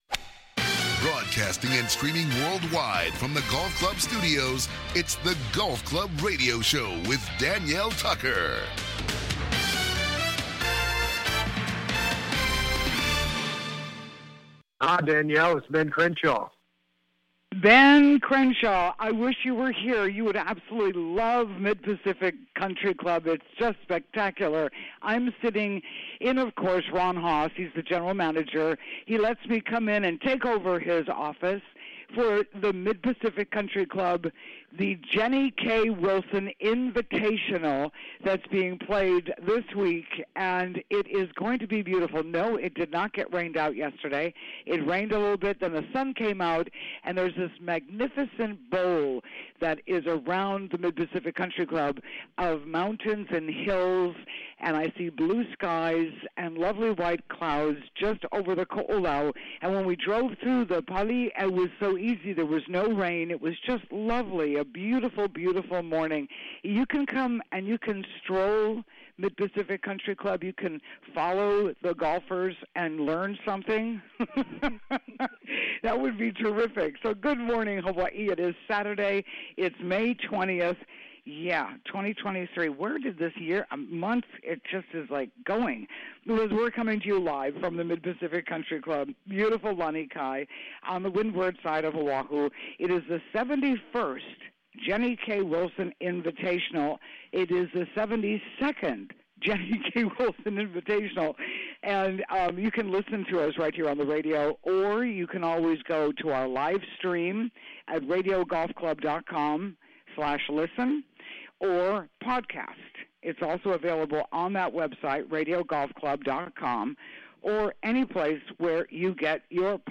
COMING TO YOU LIVE FROM THE 71 st JENNY-K WILSON TOURNAMENT� THE GOLF CLUB STUDIOS ON REMOTE OAHU�s WINDWARD COAST